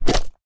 minecraft / sounds / mob / slime / attack2.ogg